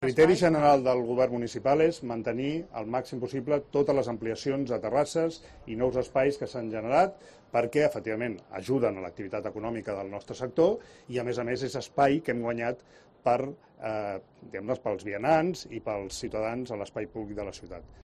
Declaraciones de Jaume Collboni respecto a las terrazas.